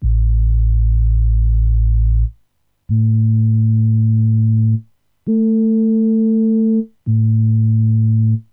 Помимо описанного, тот же самый OSC 2 с тех же примерно пор перестал строить по октавам - чем выше, тем страшнее диссонанс строя. Вот, пример одного из патчей баса в трёх октавах ноты А. Вложения Voyager 3 octaves.wav Voyager 3 octaves.wav 1,4 MB · Просмотры: 545